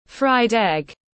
Trứng rán tiếng anh gọi là fried egg, phiên âm tiếng anh đọc là /fraɪd ɛg/
Fried egg /fraɪd ɛg/